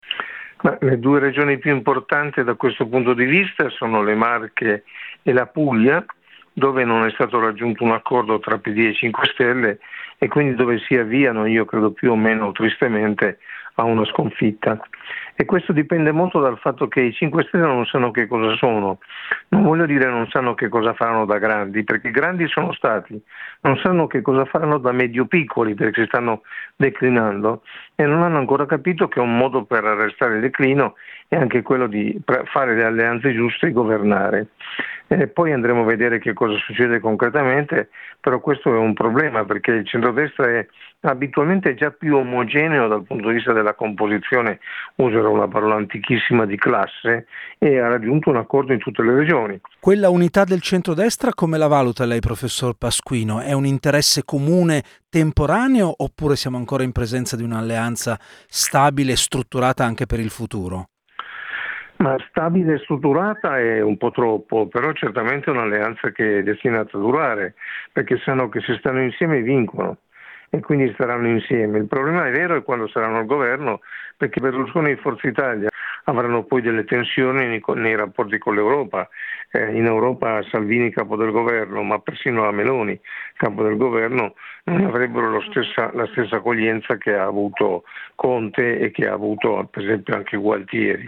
Il racconto della giornata di sabato 22 agosto 2020 attraverso le notizie principali del giornale radio delle 19.30, dai dati dell’epidemia in Italia alla chiusura dei termini per la presentazione delle liste per le prossime amministrative e regionali.
Un commento di Gianfranco Pasquino, professore emerito di scienza politica all’Università di Bologna: